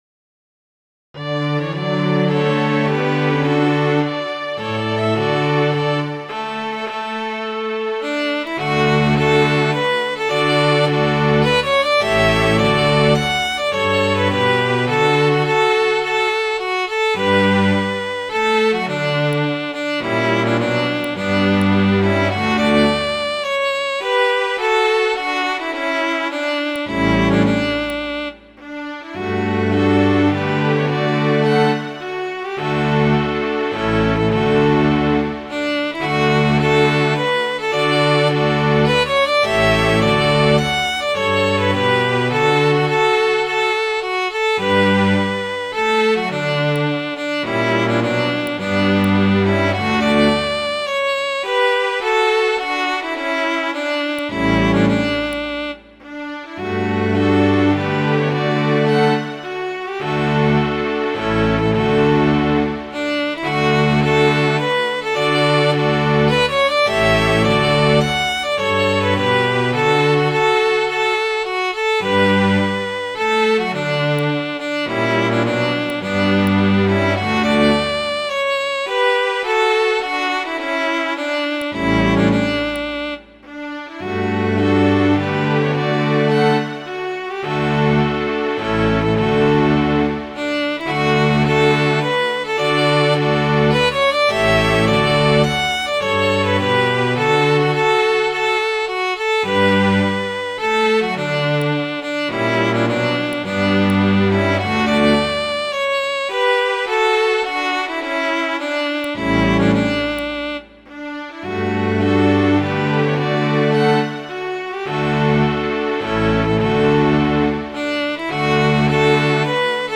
field.mid.ogg